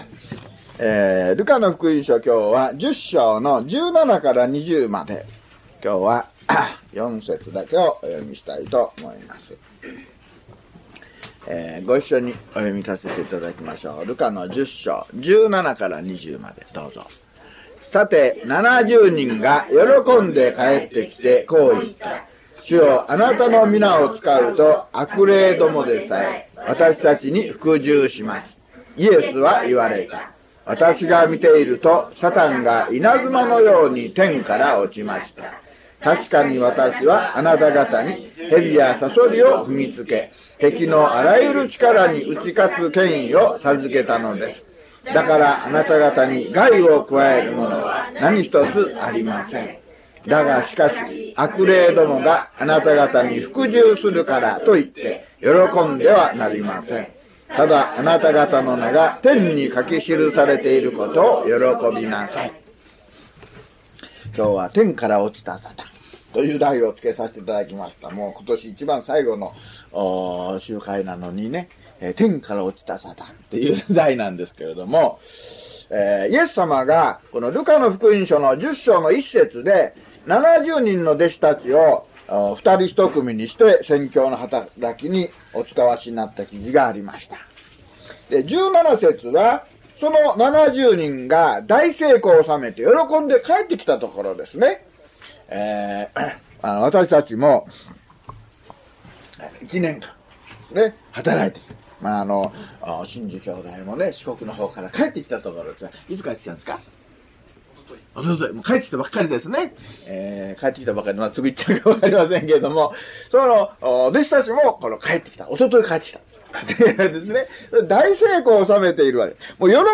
luke073mono.mp3